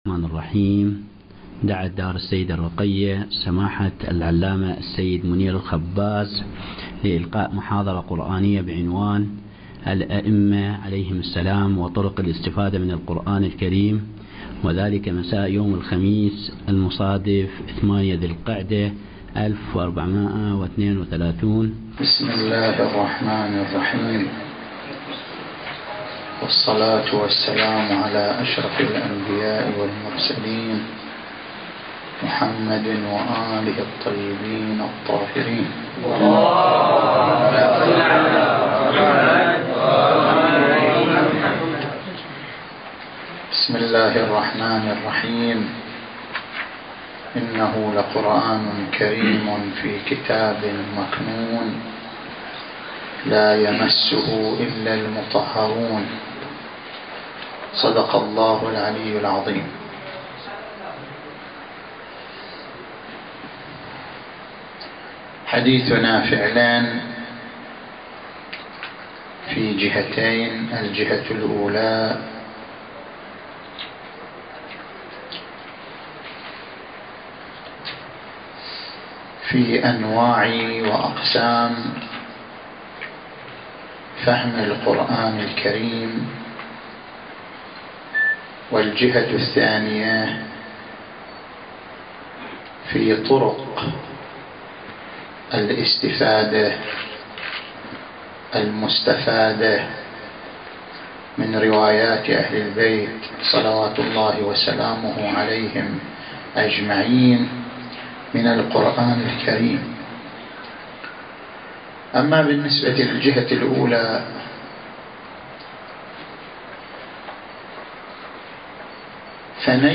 في مولد الإمام الرضا عليه السلام